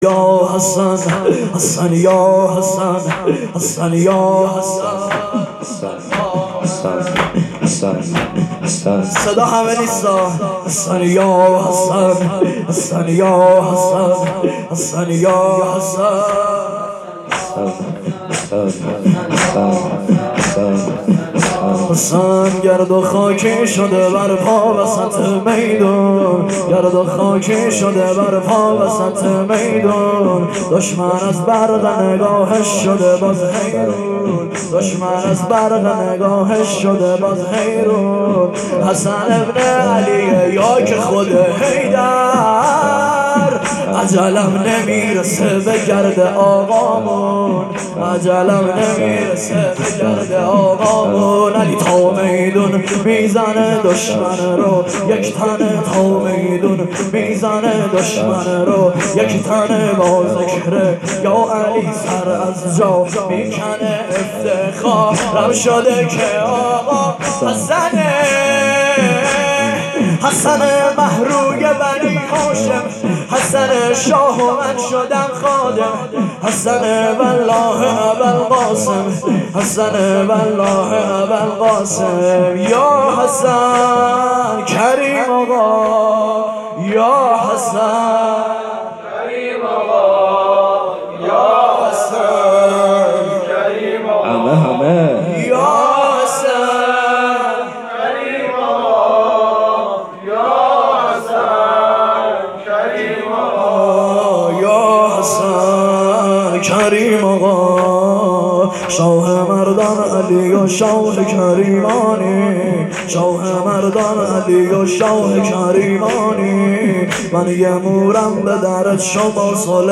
مداحی بسیار زیبا